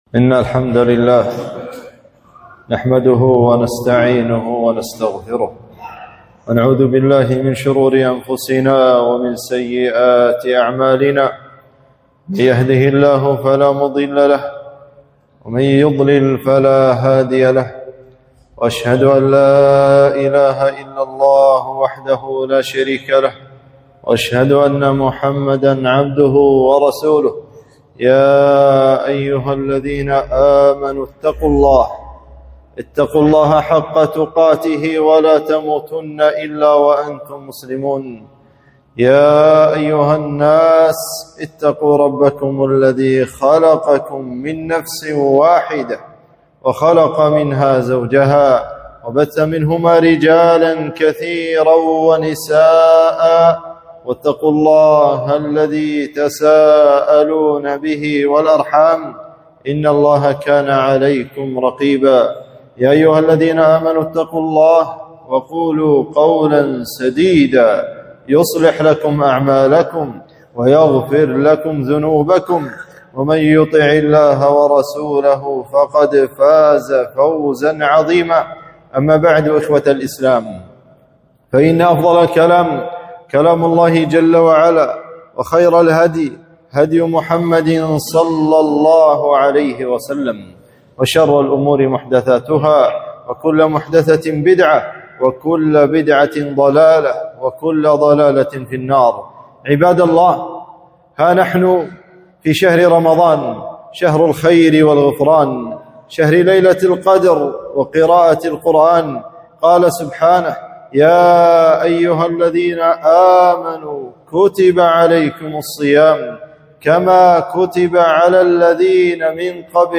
خطبة - شهر القرآن